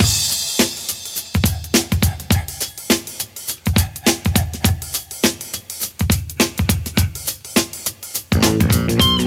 103 Bpm Breakbeat F Key.wav
Free drum beat - kick tuned to the F note. Loudest frequency: 3902Hz
103-bpm-breakbeat-f-key-cJv.ogg